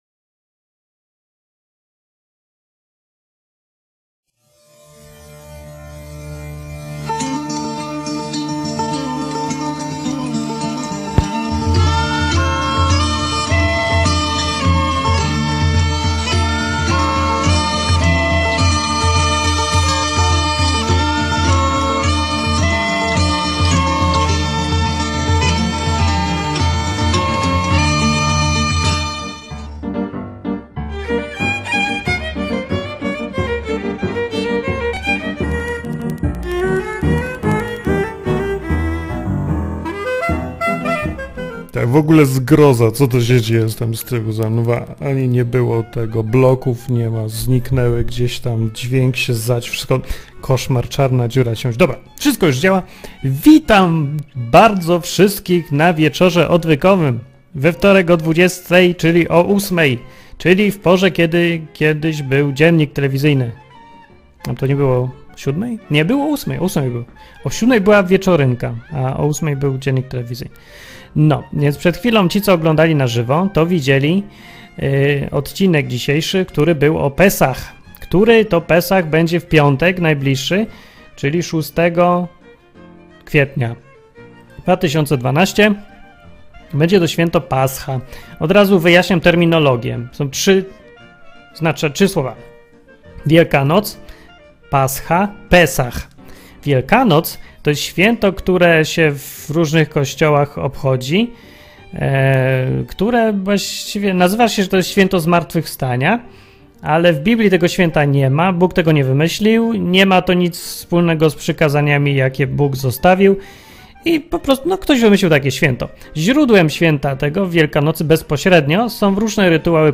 Przed Wielkanocą i przed Pesach. Rozmowy o tym co lepiej świętować.